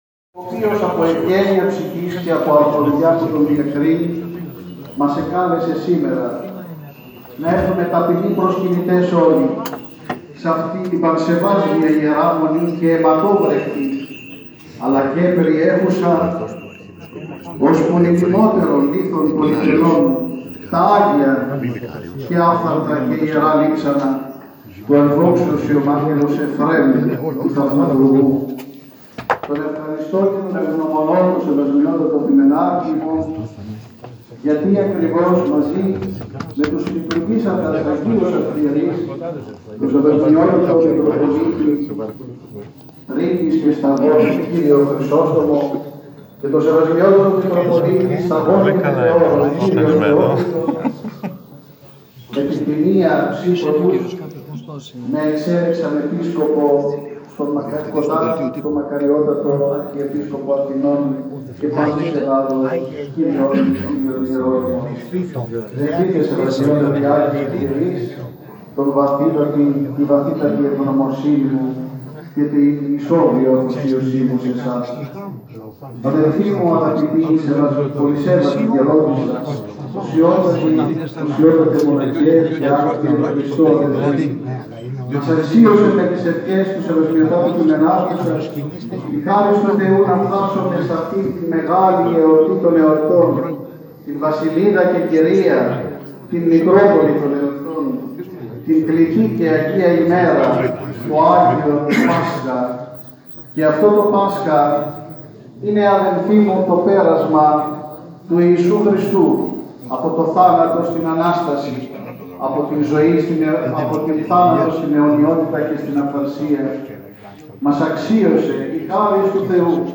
Πολυαρχιερατική Θεία Λειτουργία τελέστηκε σήμερα στην Ιερά Μονή Ευαγγελισμού της Θεοτόκου και Αγίου Εφραίμ Νέας Μάκρης.
Στήν Θεία Λειτουργία παρευρέθησαν χιλιάδες πιστοί ἀπό διάφορα μέρη τῆς Ἑλλάδος καί Ὀρθόδοξες χώρες, ὅπως Ρωσία καί Ρουμανία, ὅπου ἔχουν ἀνεγερθεῖ πολλοί Ἱεροί Ναοί ἐπ’ ὀνόματι τοῦ Ἁγίου Ἐφραίμ.